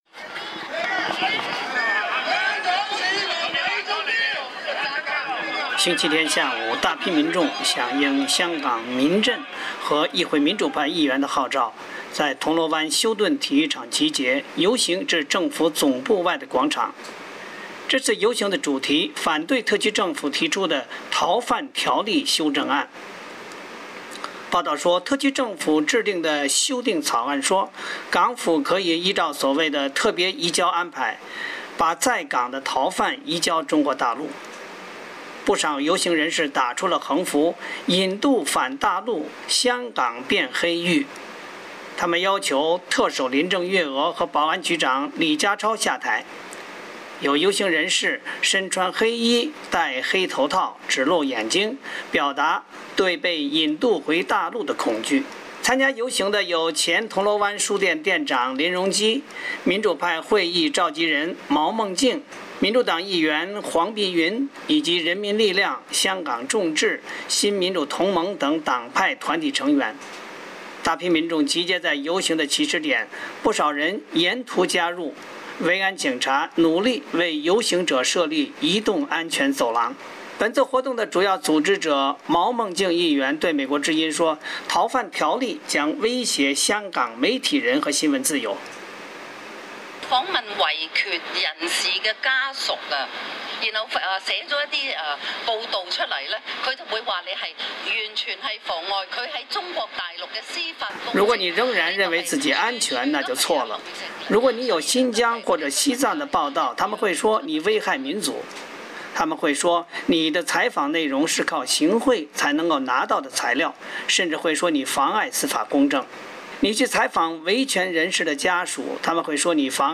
星期天下午，大批民众响应香港民阵和议会民主派议员的号召，在铜锣湾休顿体育场集结，游行至政府总部外广场。